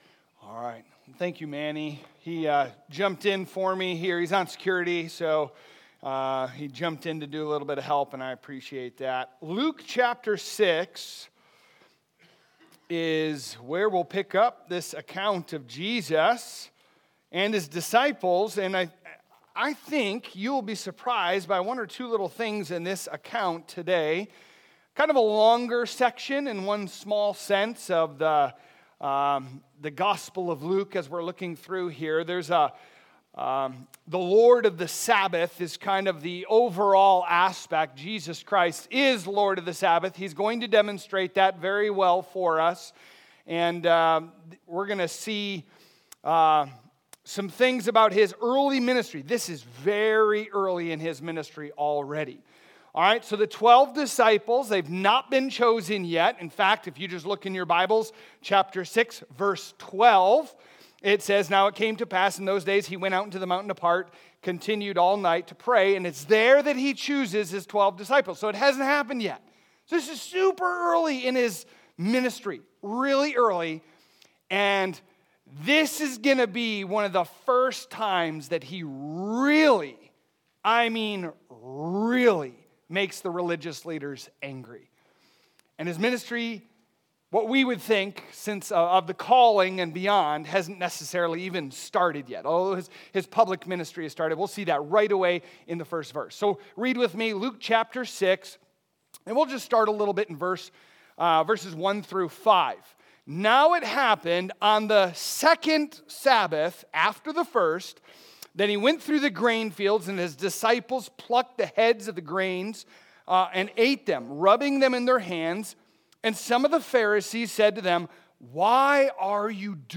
Sermons | Trinity Baptist Church